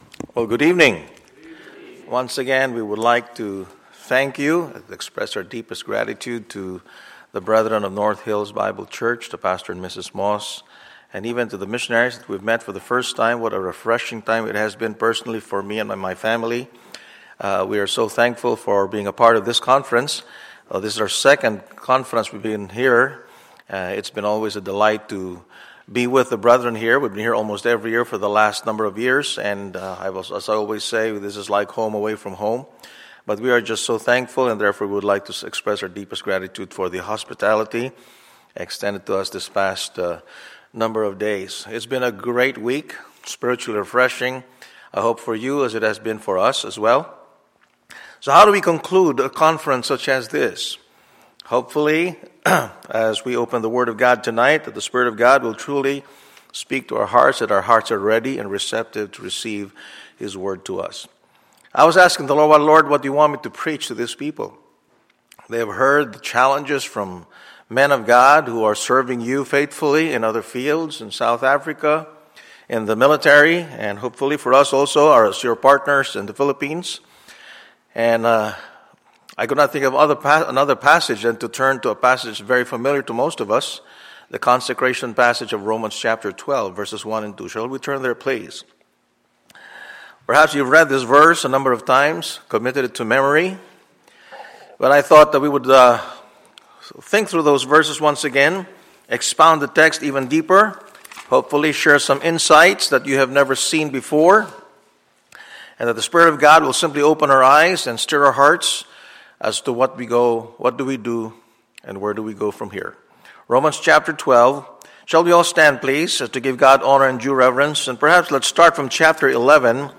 Wednesday, September 24, 2014 – Wednesday Evening Service